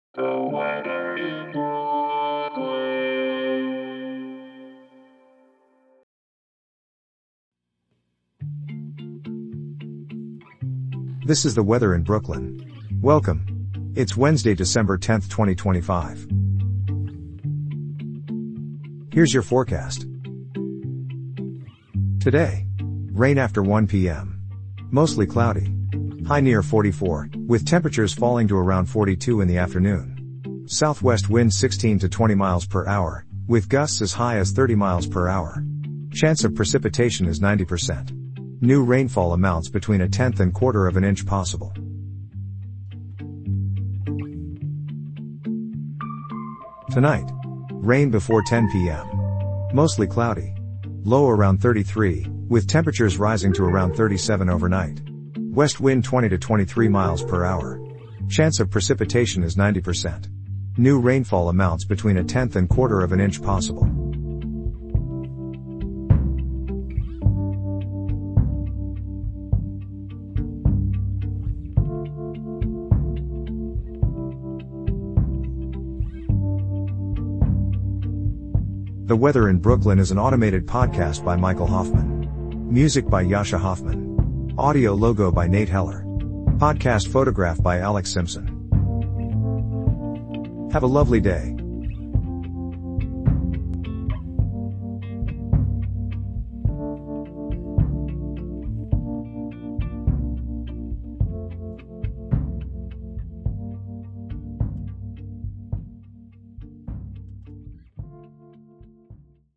An automated podcast bringing you your daily weather forecast for Brooklyn, NY.